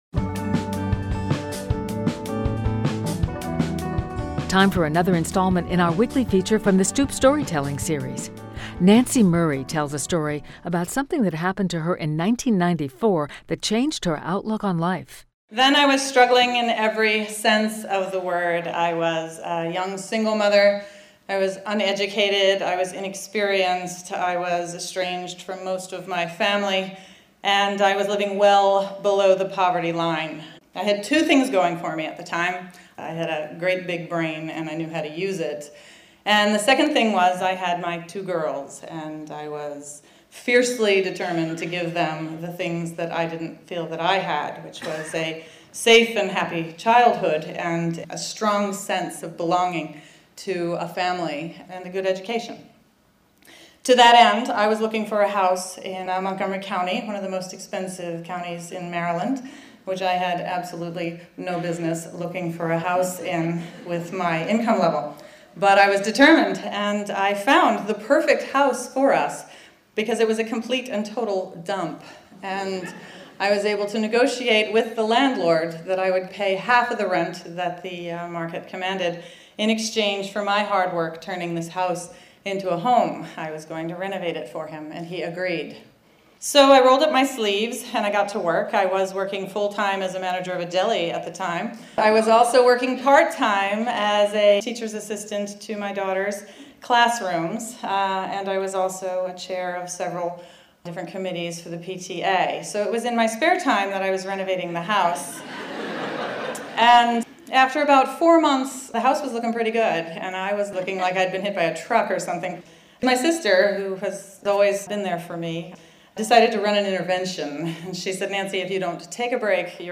Time for another installment in our weekly feature from the Stoop Storytelling Series!